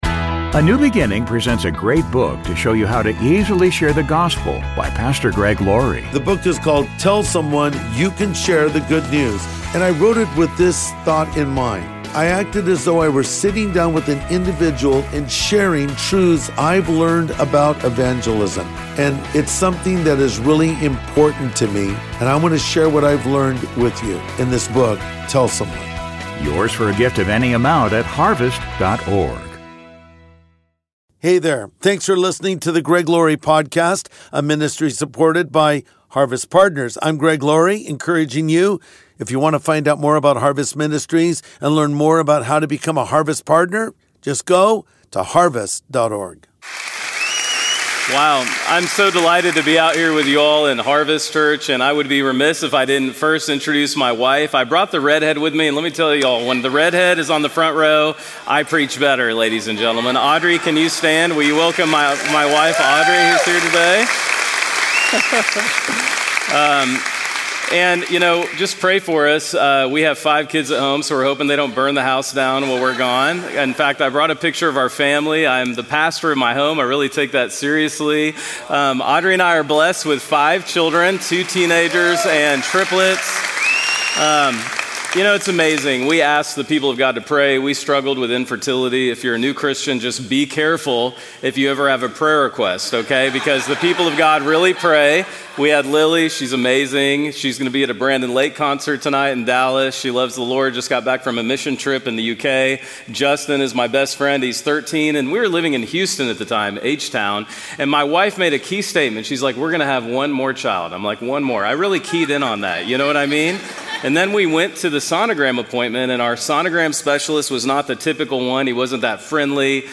Who Is the Man of the Shroud? | Sunday Message